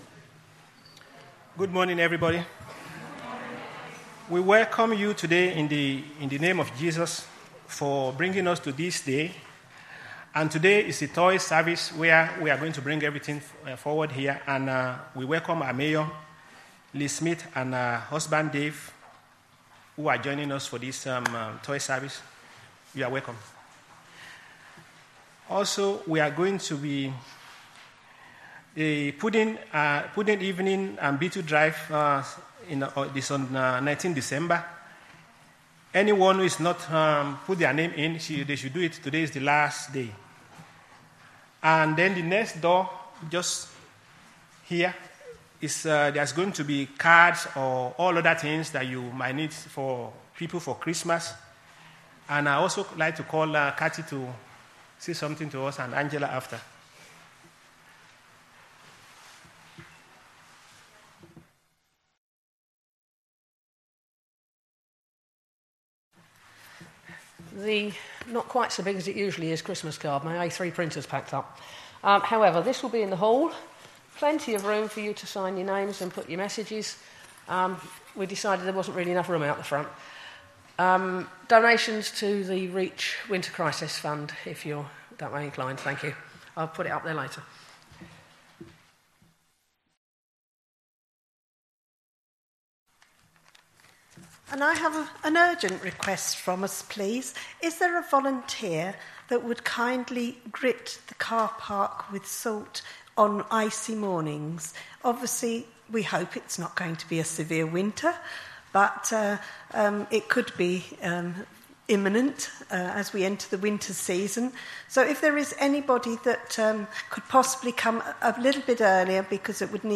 Today was our annual toy service where we bring new, unwrapped toys and gifts for children and young people (0-18yrs) known to Social Services who might otherwise have nothing at Christmas.
This was also an all age service where the children and young people played a large part in the worship.